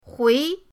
hui2.mp3